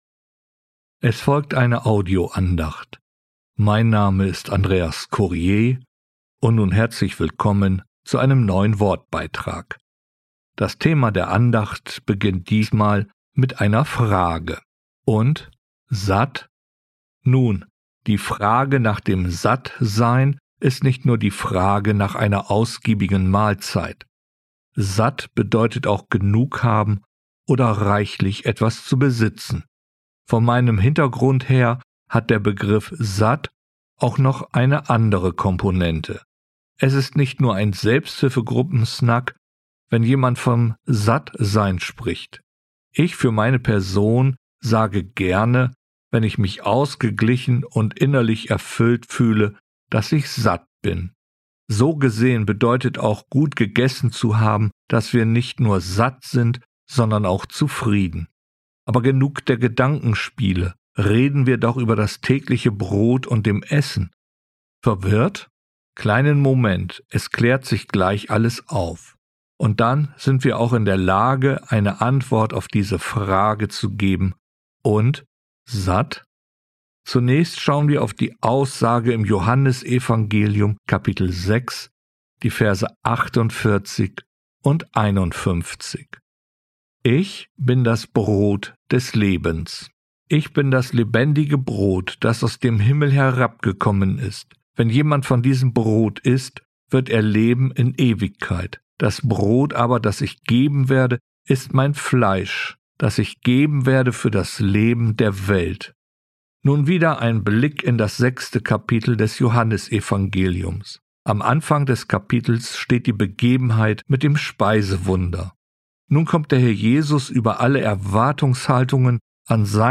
Und, satt? Eine Audio-Andacht